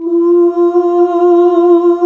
UUUUH   F.wav